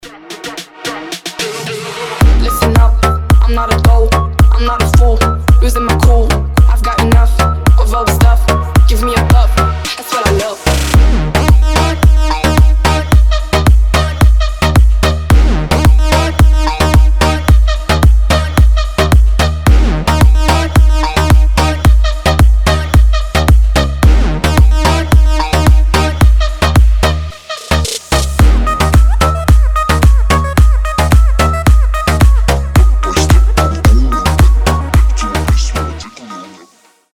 басы
восточные
быстрые
арабские
Четкий arabic trap